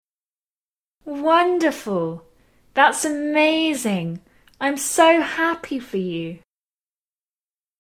Learn the High Fall pitch pattern in British RP
We use the high fall when reacting to great news.
The high fall serves to show genuine interest and engagement, in this case, demonstrating that you are genuinely happy for your friend who has great news: